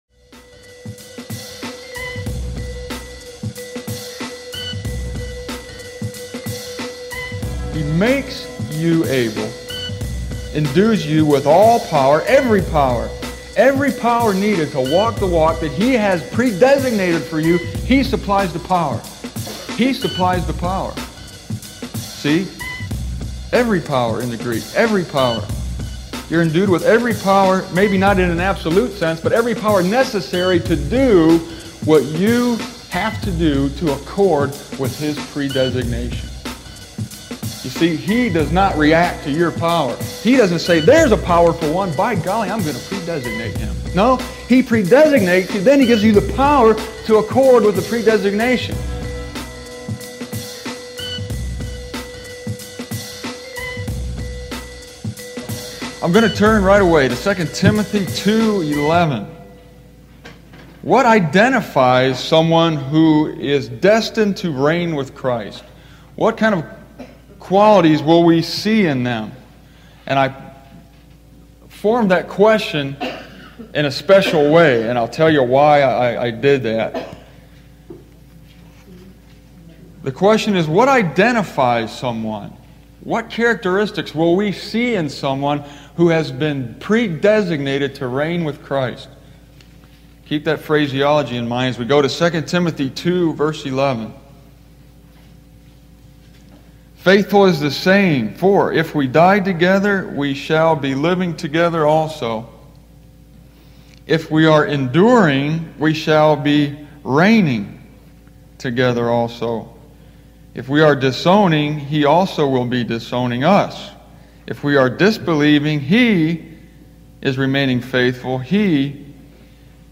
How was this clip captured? I recorded this message in the summer of 1997 in Baldwin, Michigan, at a little chapel behind a Tru Valu Hardware store.